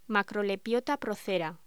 Locución: Macrolepiota procera
voz